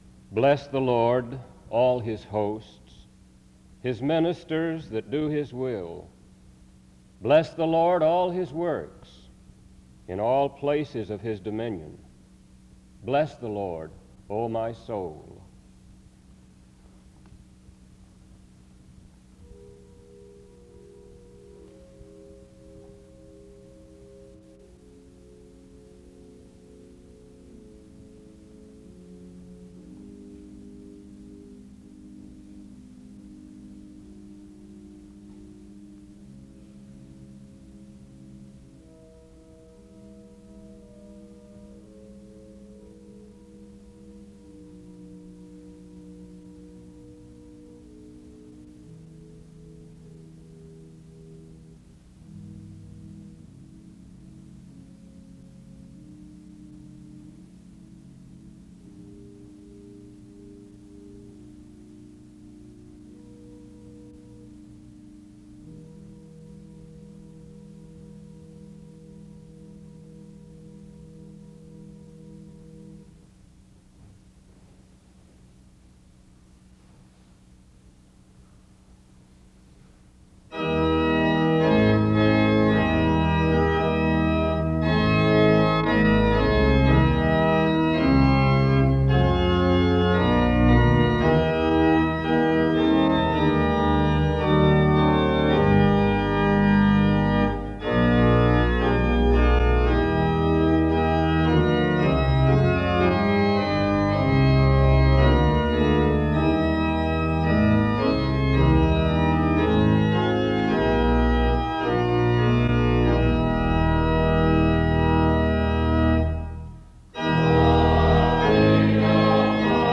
The service begins with a scripture reading from 0:00-0:15. Music plays from 0:16-4:05. A prayer is offered 4:15-5:47.
Galatians 6:1-10 is read from 5:55-7:48.
His message is on reaping what one sows and how God is at work over our labor. The service closes with music from 28:00-28:57.